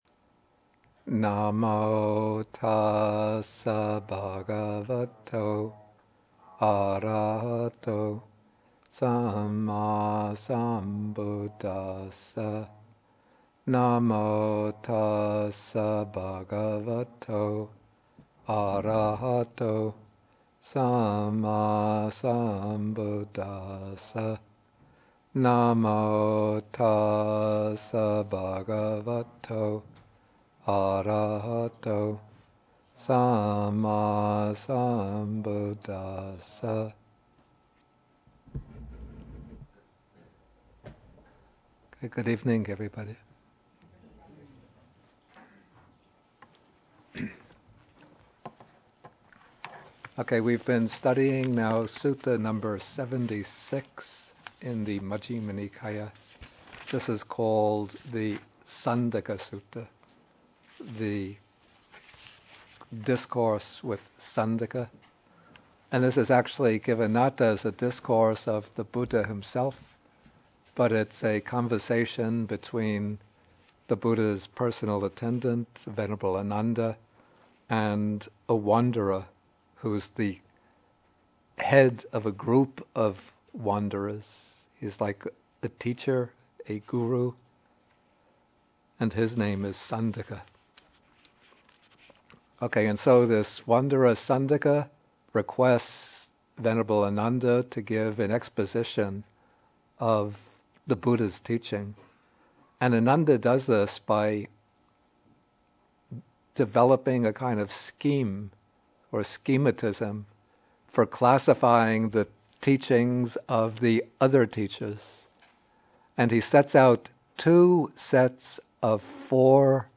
MN76, Bhikkhu Bodhi at Bodhi Monastery (lectures 152 and 153)